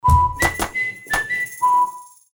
La Marca sonora está compuesta por un sonido o combinación de estos.
Tipo de marca sonora
Ejemplo-registro-marca-sonora.mp3